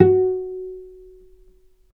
healing-soundscapes/Sound Banks/HSS_OP_Pack/Strings/cello/pizz/vc_pz-F#4-mf.AIF at b3491bb4d8ce6d21e289ff40adc3c6f654cc89a0
vc_pz-F#4-mf.AIF